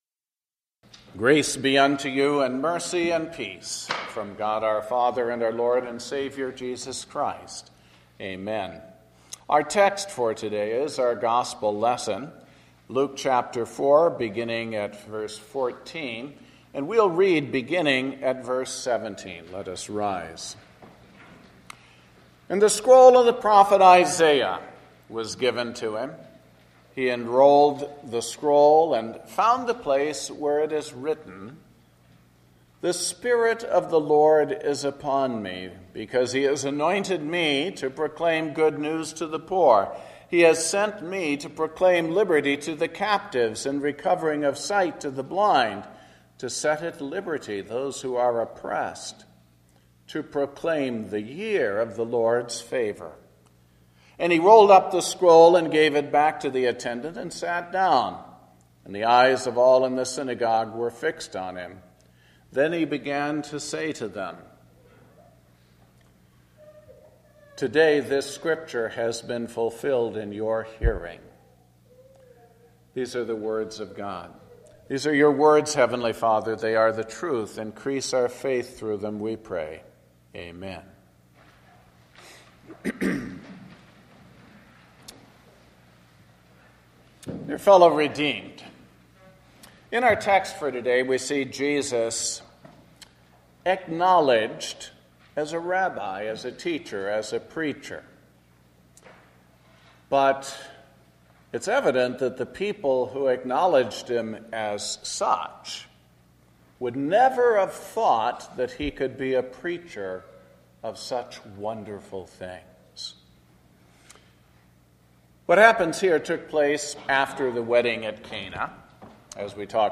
Sermon based on Luke 4:14-21